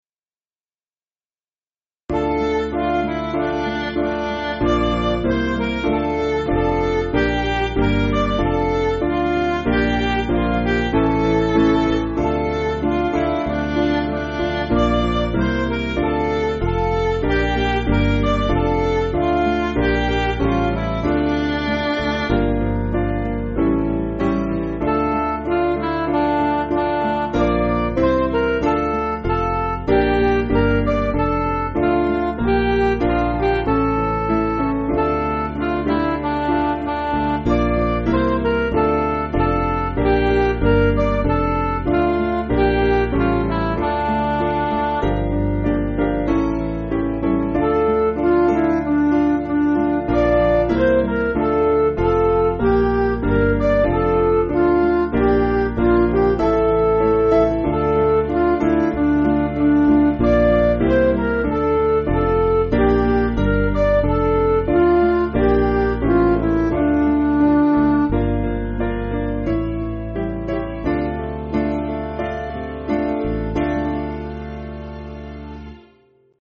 Piano & Instrumental
(CM)   3/Dm